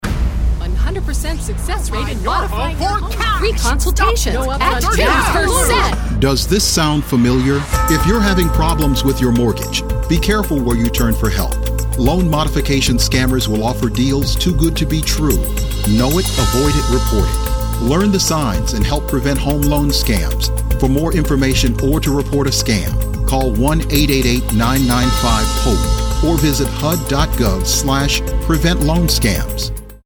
loanscamekit_eng-radio-30.mp3